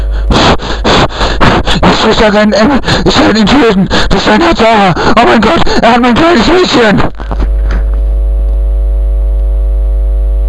Das letzte Lebenszeichen von Ihm war dieser verwirrte Anruf:
Hilferuf